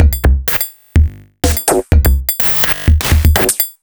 Metal Edge 03.wav